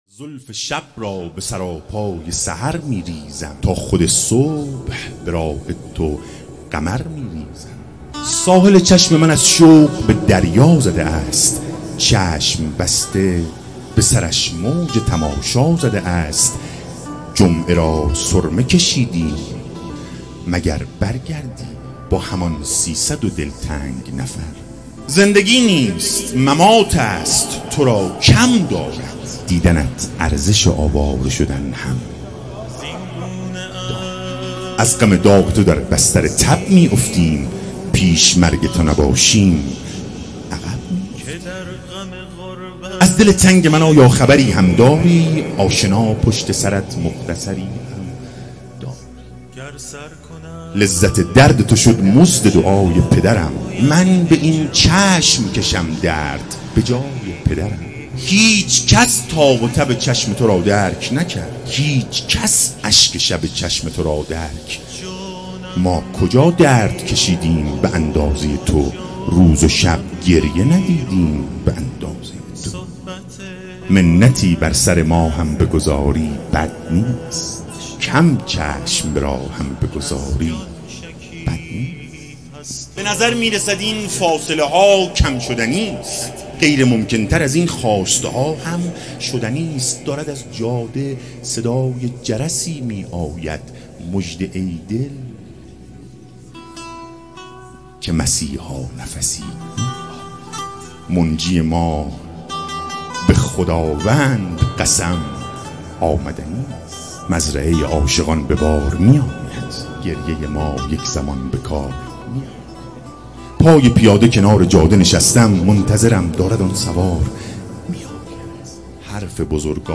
ولادت حضرت عباس (ع) هیئت مکتب العباس(ع) خمینی شهر